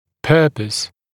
[‘pɜːpəs][‘пё:пэс]цель, намерение